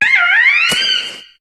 Cri de Ouisticram dans Pokémon HOME.